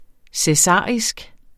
Udtale [ sεˈsɑˀisg ]